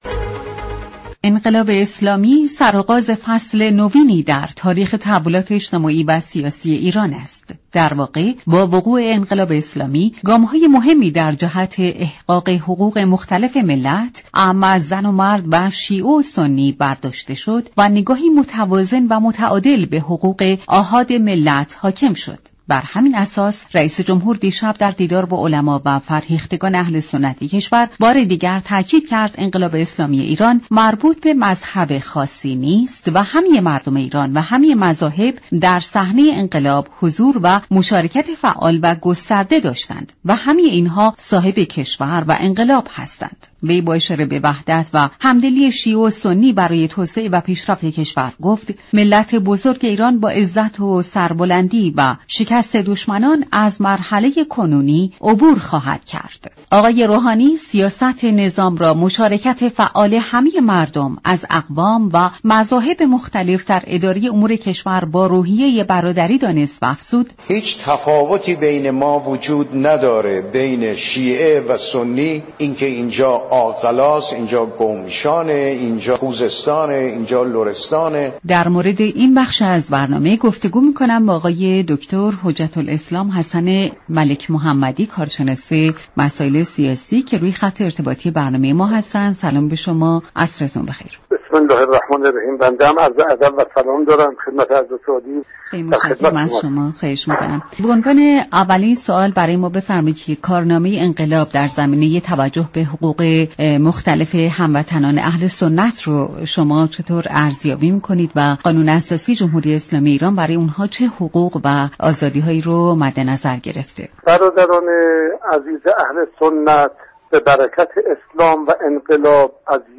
رویداد